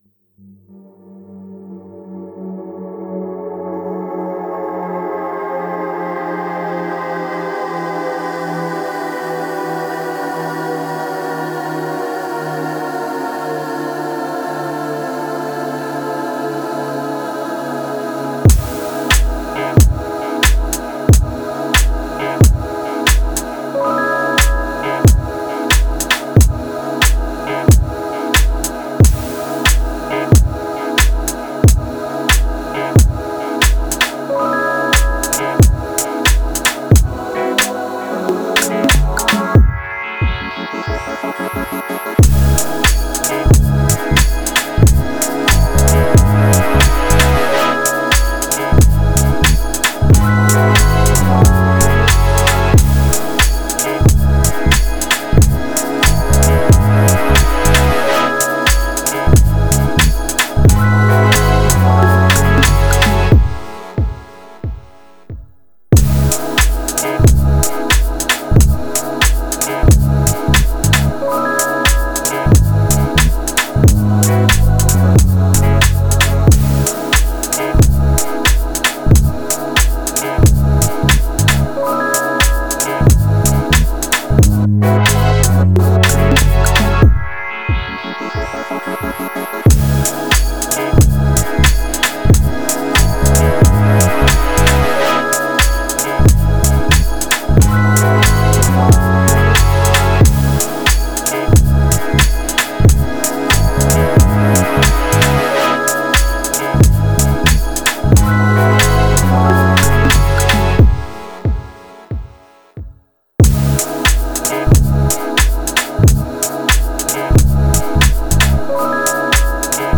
I am a French beat maker/musician since 2001.